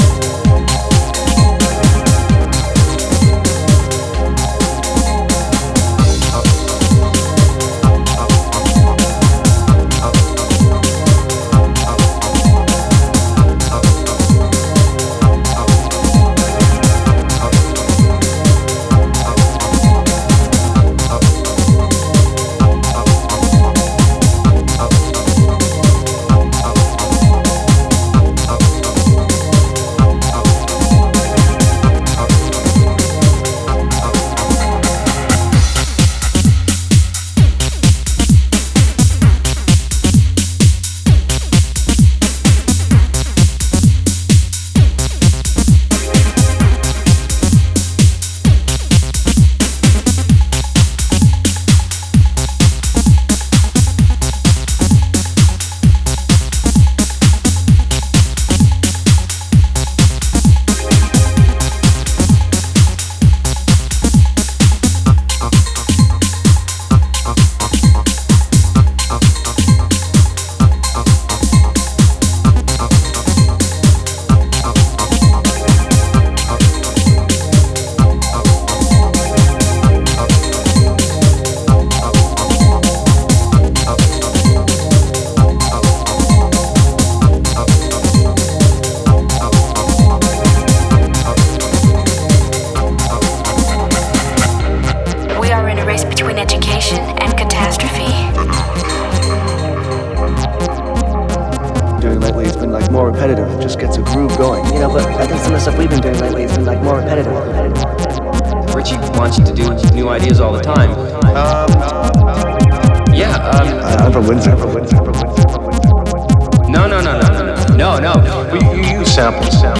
house tracks. All tunes were recorded in Montmartre, Paris